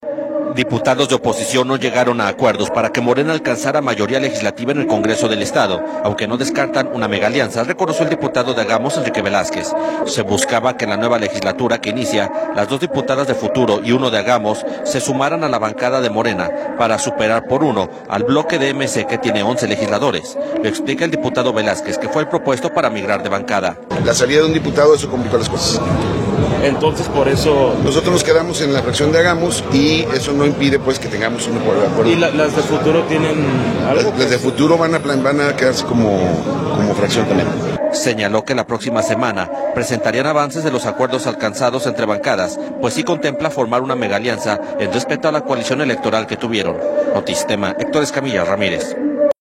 Lo explica el diputado Velázquez, que fue el propuesto para migrar de bancada.